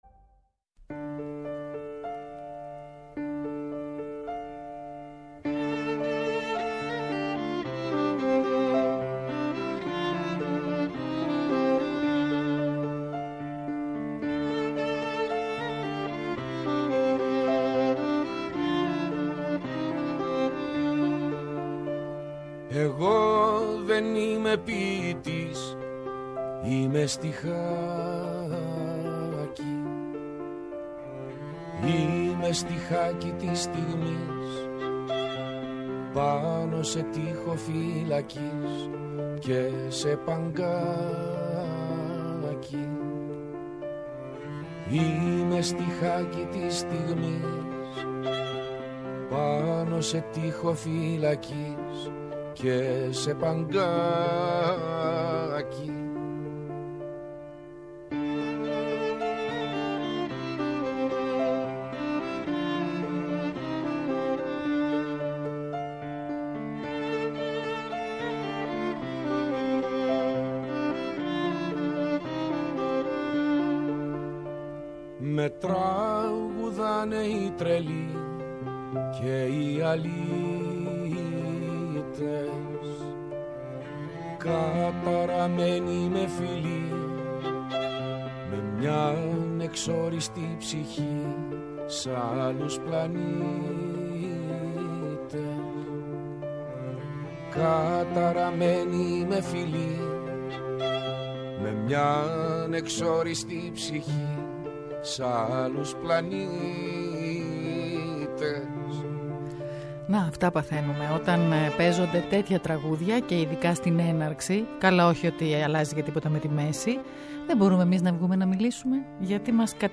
Απόψε καλεσμένες στο στούντιο οι ποιήτριες